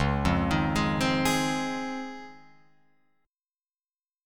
C#M9 Chord